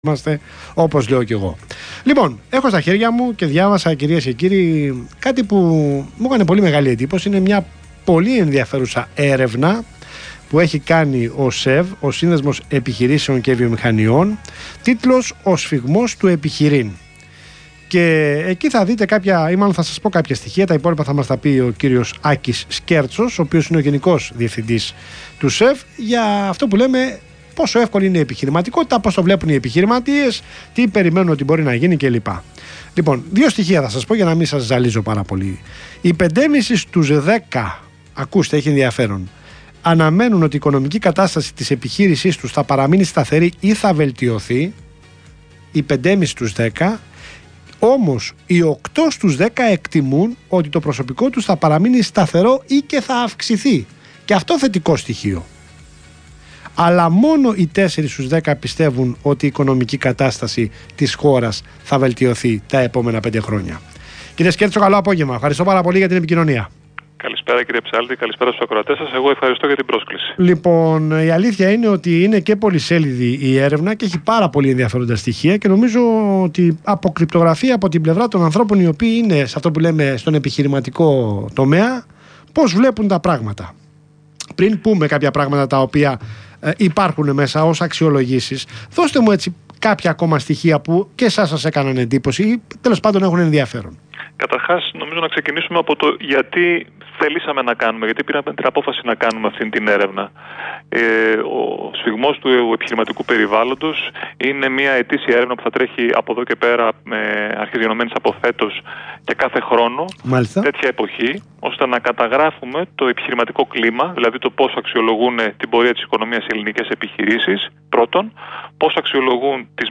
Συνέντευξη του Γενικού Διευθυντή του ΣΕΒ, κ. Άκη Σκέρτσου στον Ρ/Σ REAL FM, σχετικά με την Έρευνα "Ο Σφυγμός του Επιχειρείν", 18/7/2017